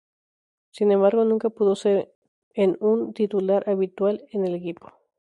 /abiˈtwal/